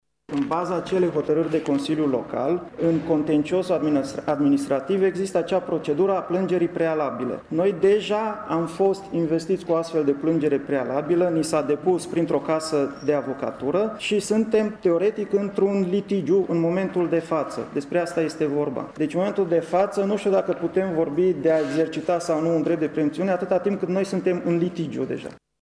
Secretarul municipiului Tîrgu-Mureș, Andrei Mureșan: